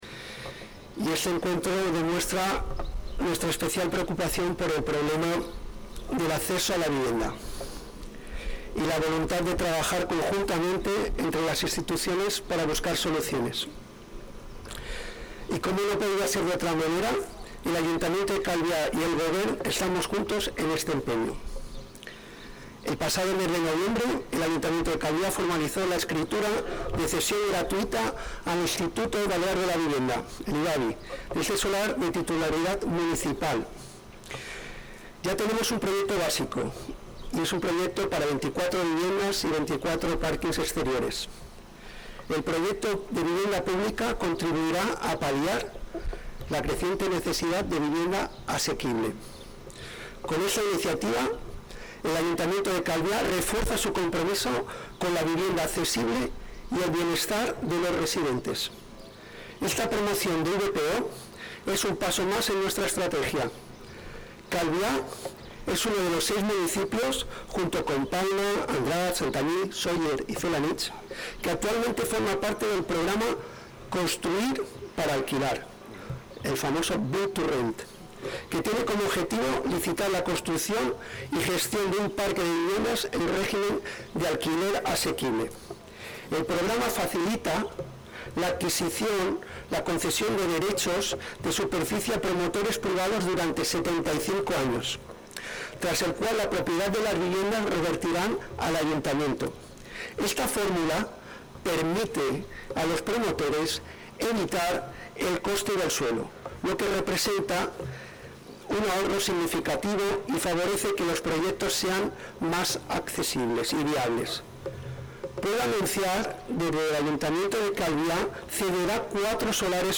declaraciones-del-alcalde.mp3